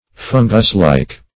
\fun"gus*like`\